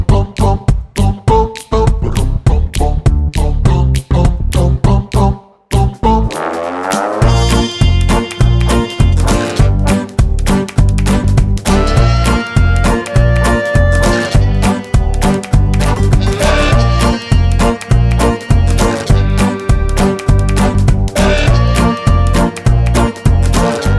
no Backing Vocals or sample Jazz / Swing 4:00 Buy £1.50